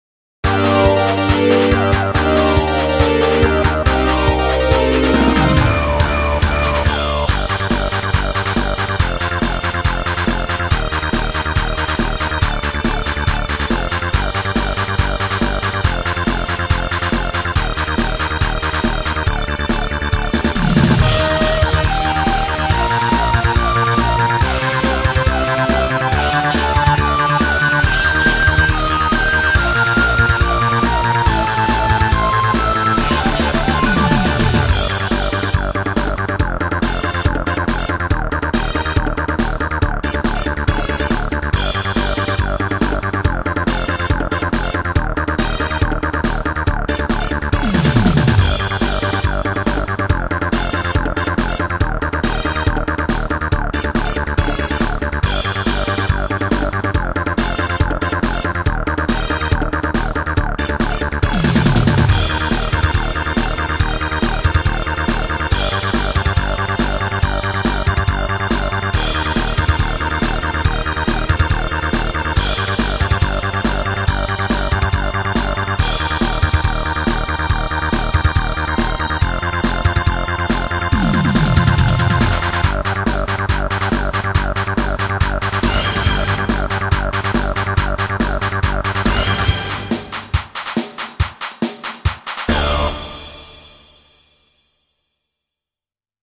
それ以外のパートがほぼ完成してるカラオケ状態です。
※音源はＳＣ８８Ｐｒｏ